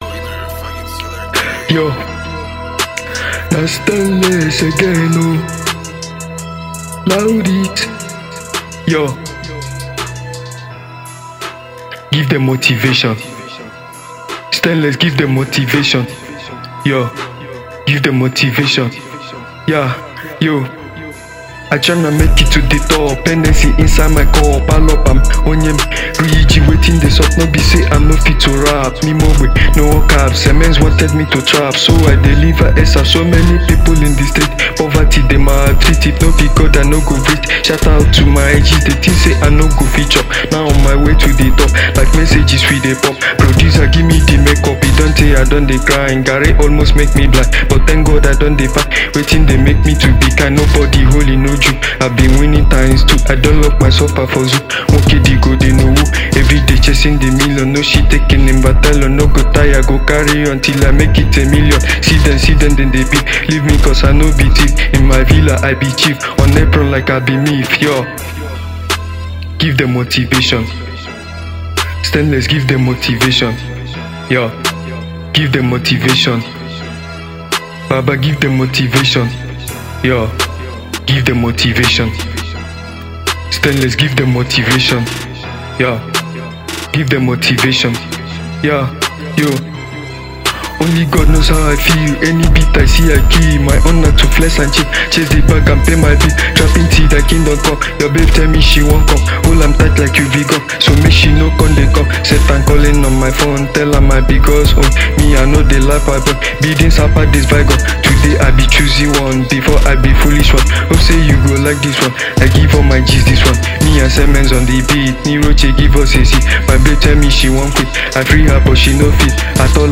Afrobeat and trap